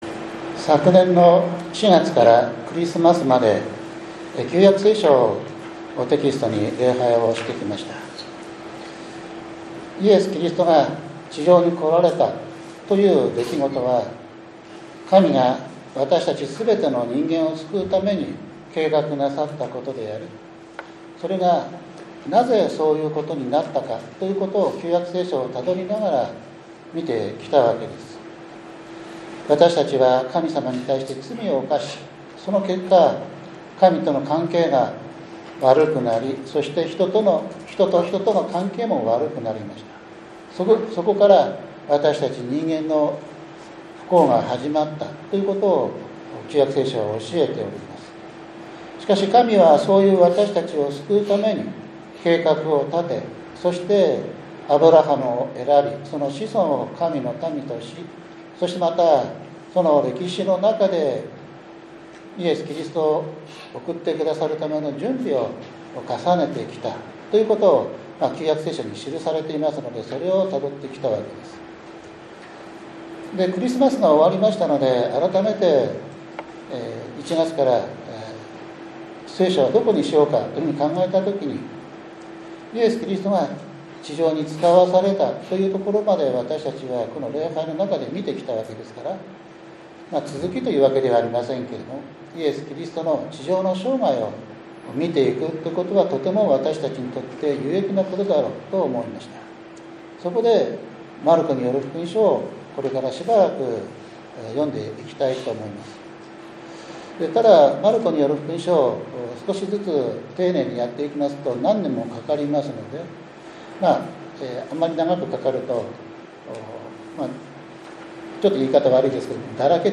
１月５日（日）主日礼拝 エレミヤ書２９章１０節～１４節 マルコによる福音書１章１節～１５節